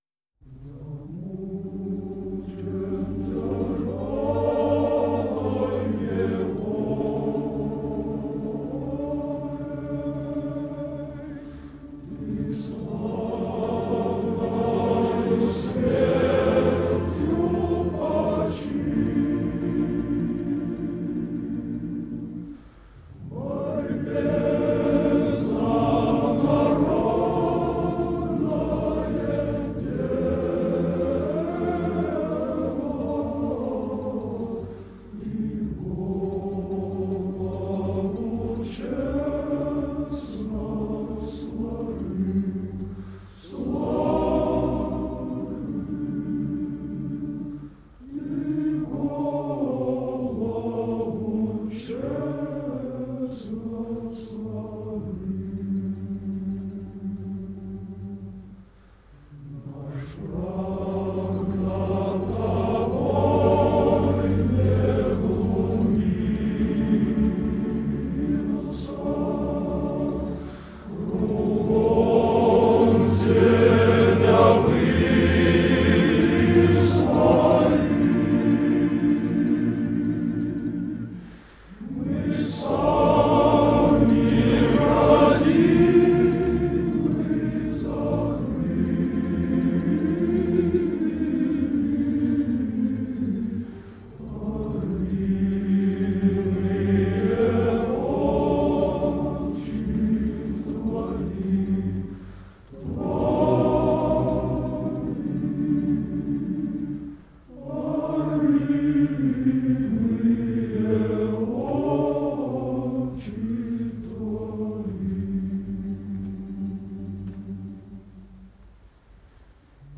Музыка: обр. Л. Шульгина Слова: Г. Мачтет
hor-krasnoy-armii-the-red-army-choir---zamuchen-tyajeloy-nevoley-(slavery-and-suffering).mp3